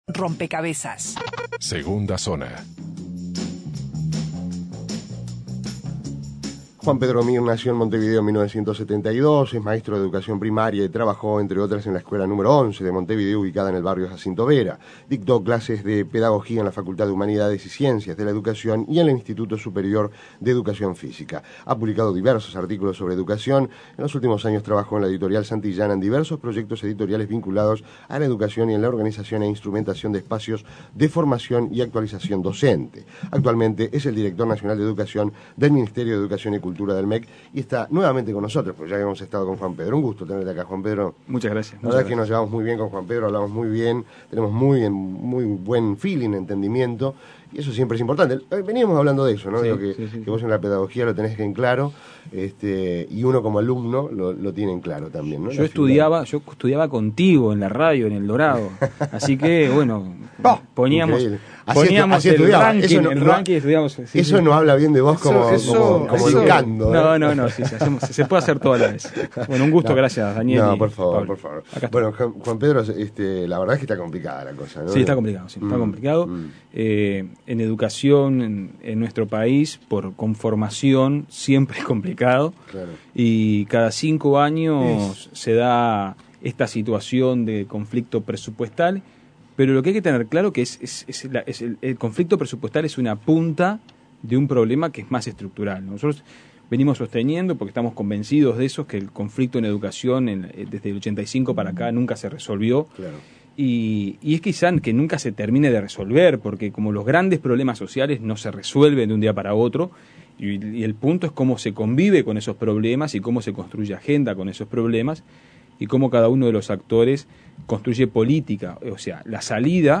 El jerarca aseguró que los recursos para la educación aumentarán y manifestó su deseo de seguir negociando con los sindicatos de la enseñanza para encontrar una salida consensuada. Descargar Audio no soportado Entrevista Juan Pedro Mir